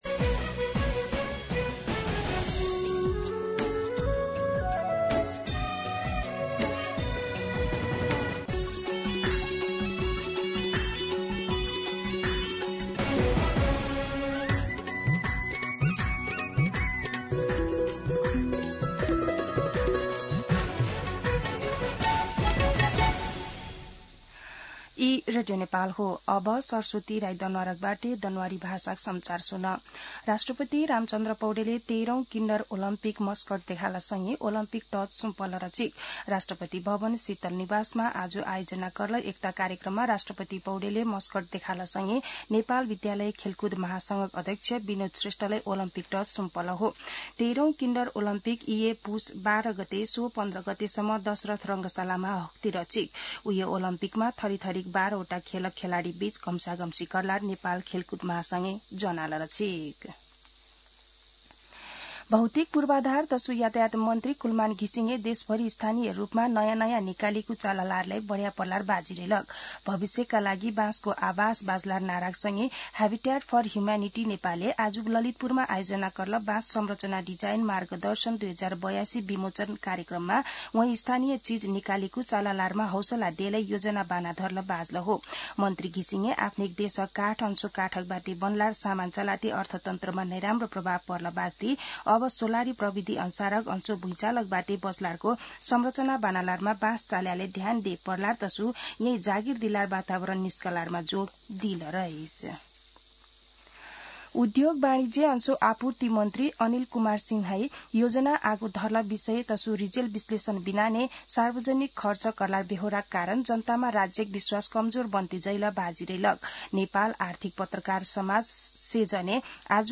दनुवार भाषामा समाचार : ४ पुष , २०८२
Danuwar-News-9-4.mp3